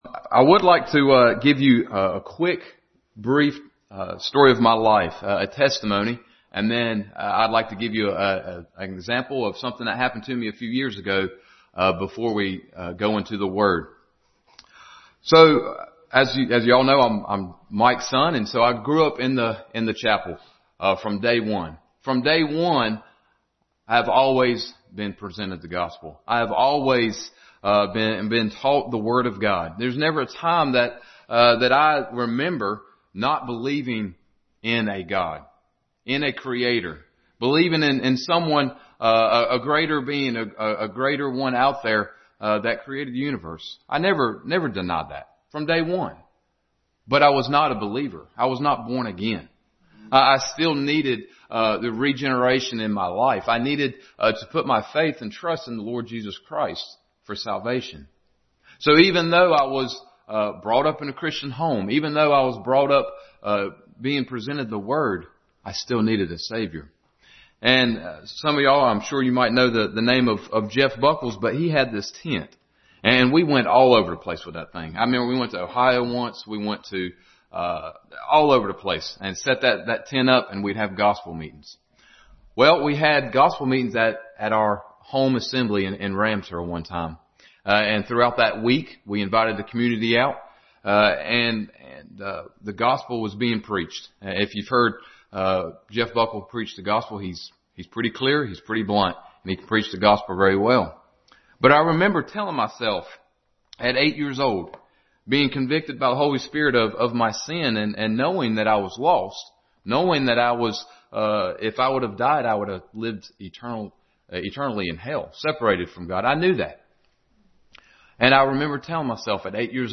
Personal Testimony – Song of Moses and the Children of Isreal Passage: Exodus 15:1-21, 2 Timothy 1:7, Exodus 14:13, Ruth 3:18, Revelation 5:9-10 Service Type: Sunday School